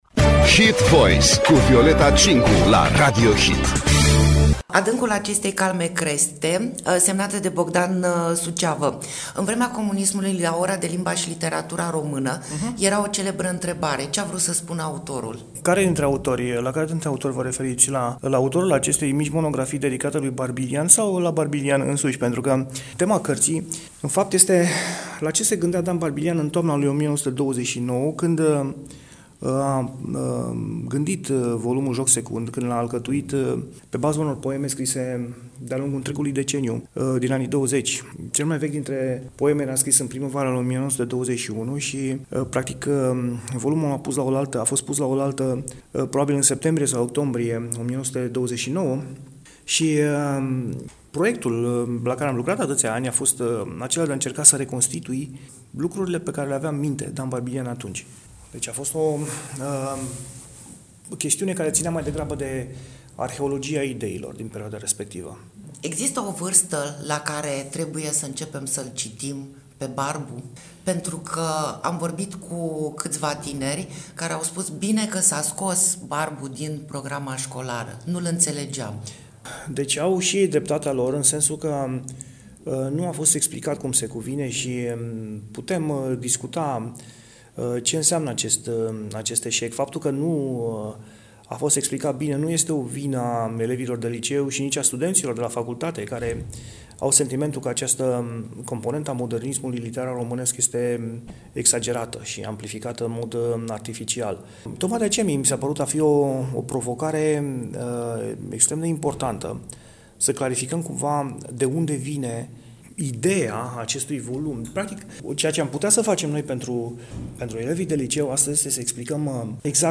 INTERVIU.